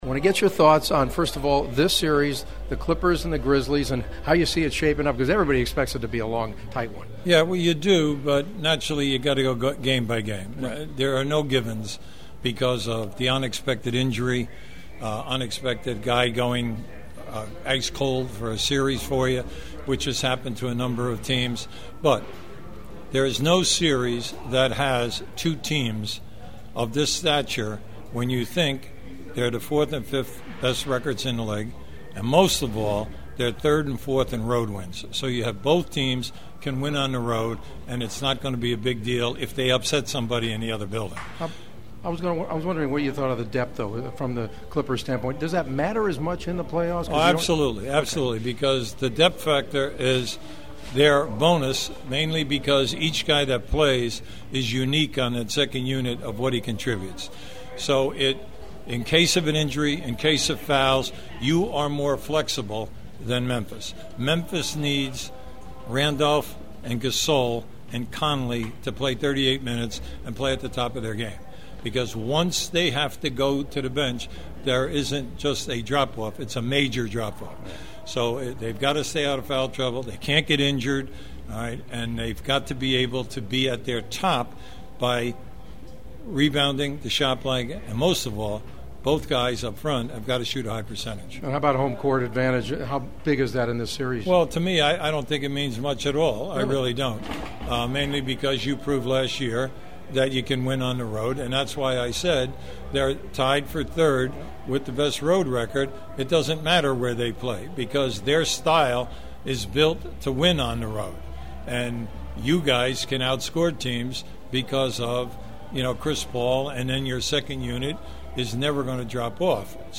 We had a chance to speak with Clippers coach Vinny Del Negro before the game and then I ran into ESPN analyst and Hall of Fame coach Hubie Brown in the hallway on his way to the booth and he was kind enough to give me a few minutes of analysis for both the Clips-Griz and Lakers-Spurs series.